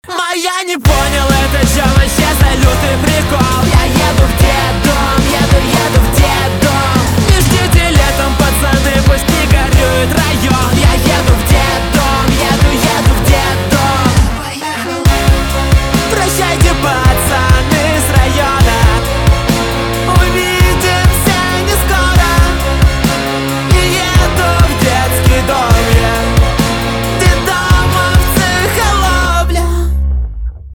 альтернатива
жесткие
гитара , барабаны
грустные